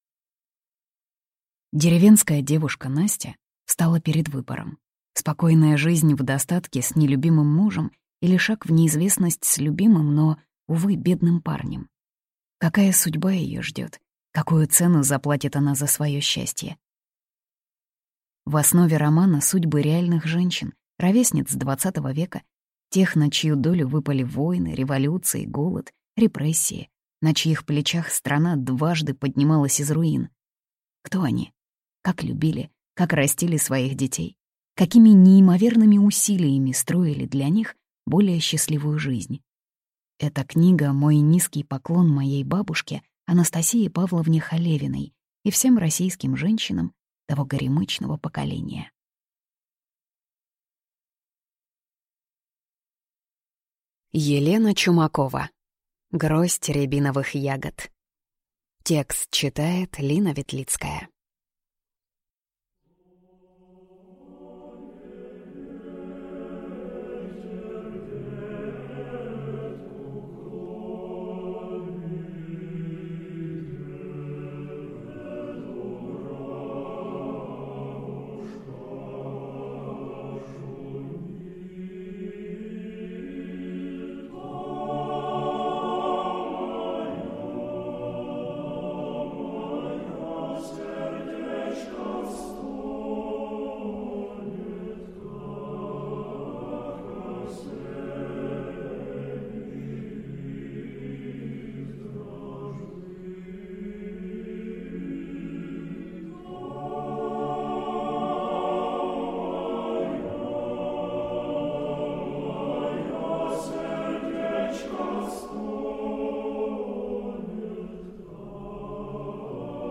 Аудиокнига Гроздь рябиновых ягод | Библиотека аудиокниг